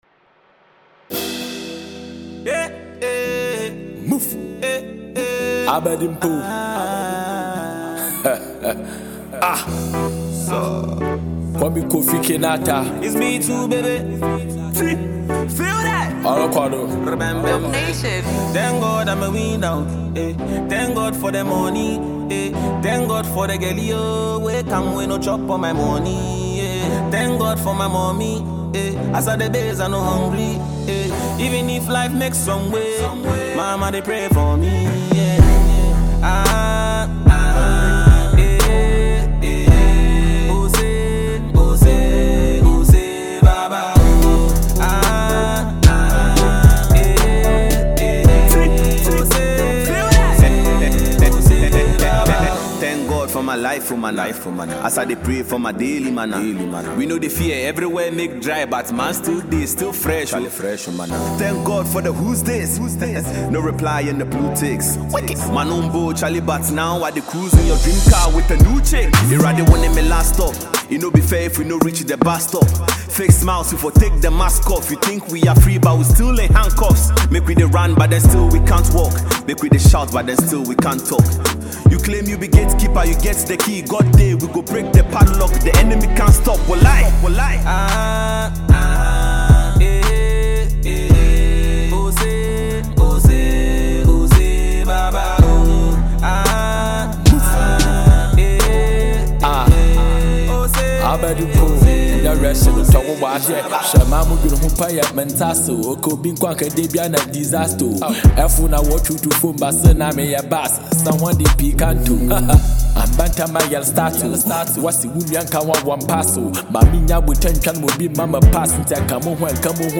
Ghanaian multiple award-winning music duo